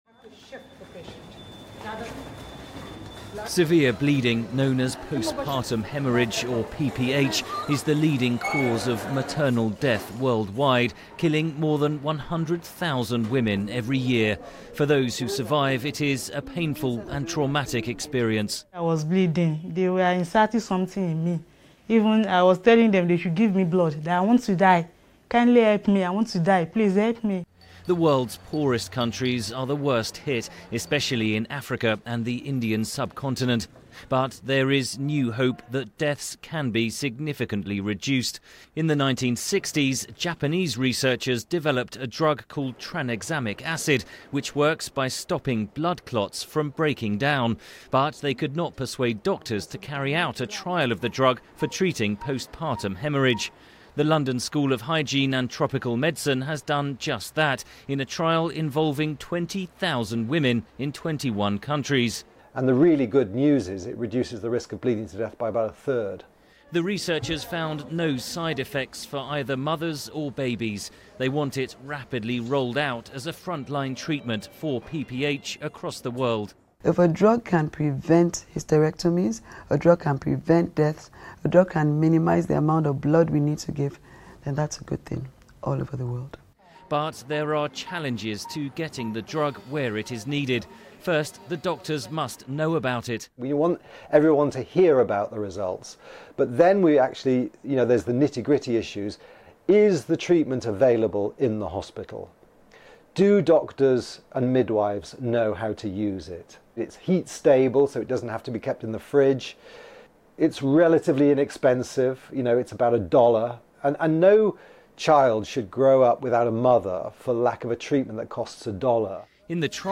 ·On-line English TV ·English publication ·broadcasting station ·Classical movie ·Primary English study ·English grammar ·Commercial English ·Pronunciation ·Words ·Profession English ·Crazy English ·New concept English ·Profession English ·Free translation ·VOA News ·BBC World News ·CNN News ·CRI News ·English Songs ·English Movie ·English magazine
News